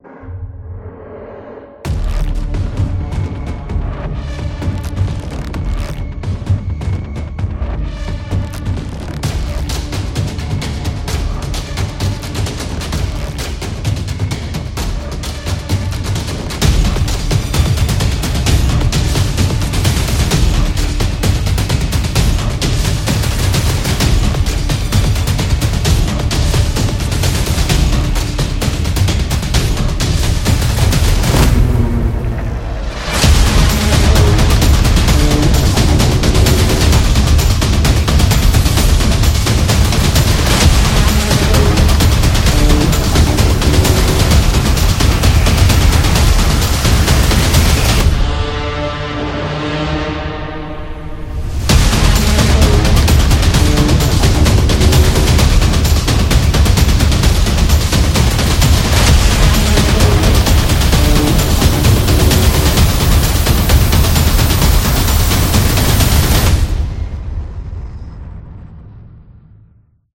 Cinematic